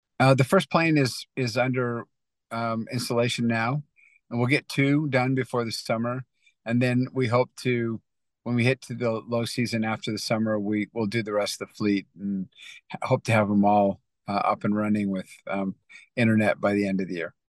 Executive Spotlight: “We can go a long way.” Breeze Airways founder David Neeleman – AeroTime